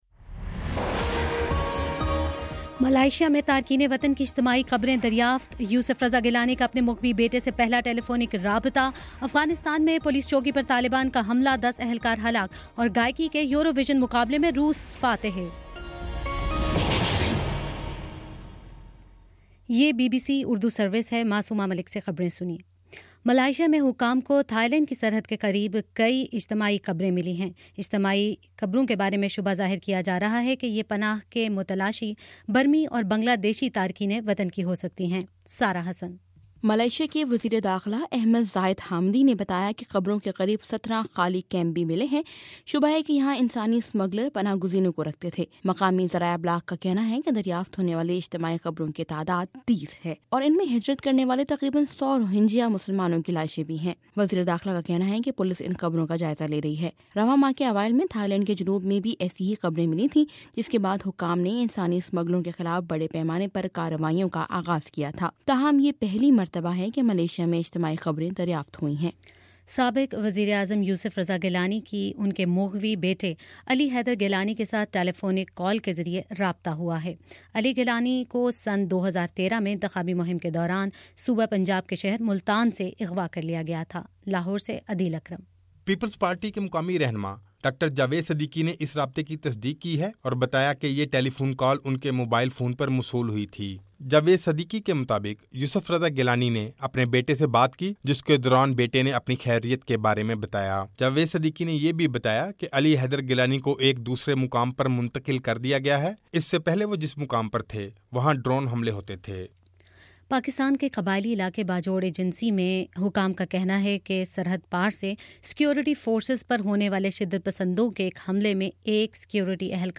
مئی 24: شام پانچ بجے کا نیوز بُلیٹن